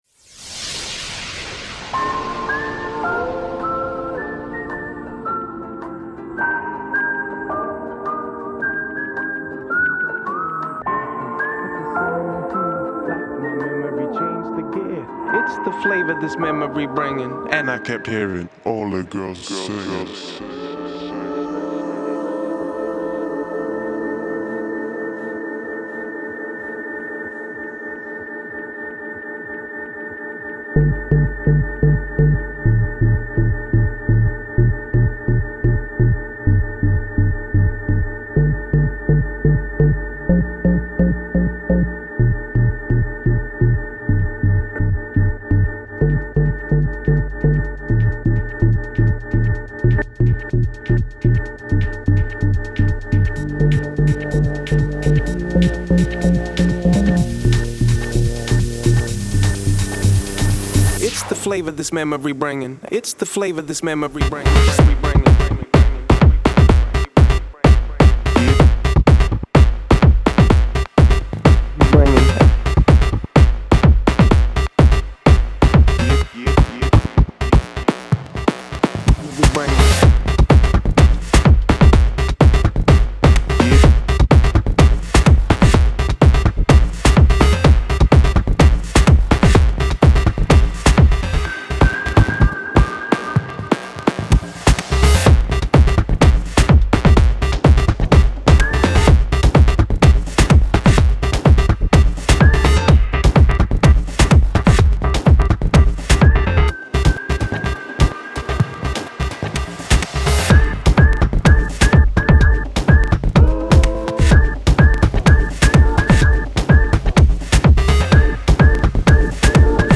Genre: Deep House, Downtempo.